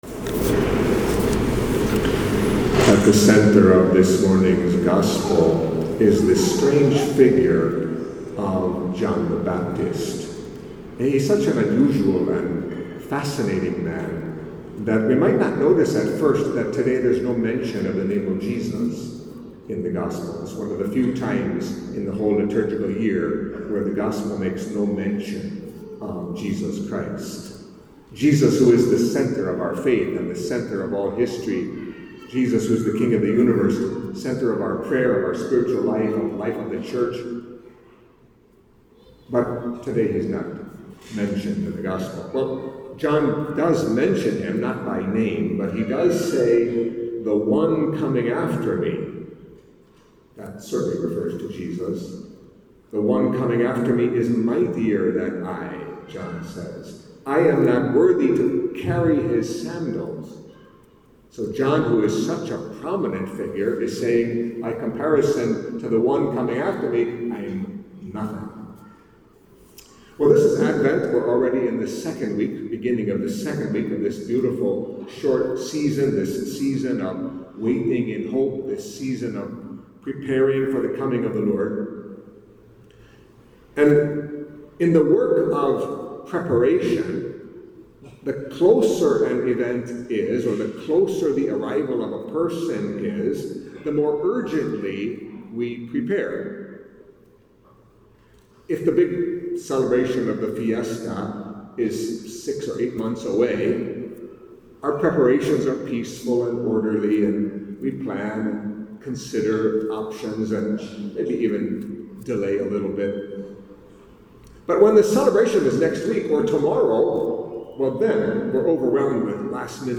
Catholic Mass homily for Second Sunday of Advent